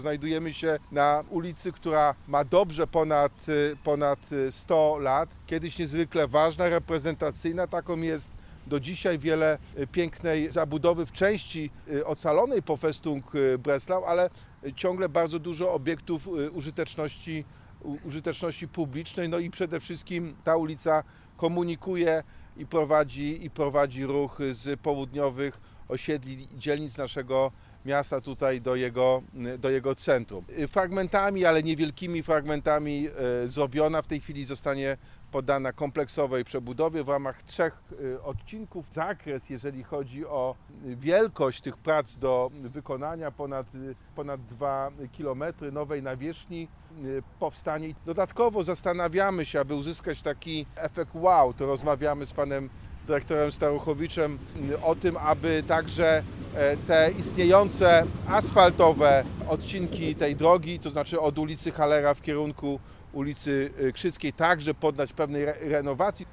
O potrzebie inwestycji mówi prezydent Wrocławia Jacek Sutryk.